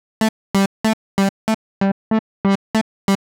Index of /musicradar/uk-garage-samples/142bpm Lines n Loops/Synths